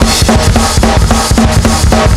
Index of /m8-backup/M8/Samples/musicradar-metal-drum-samples/drums acoustic/220bpm_drums_acoustic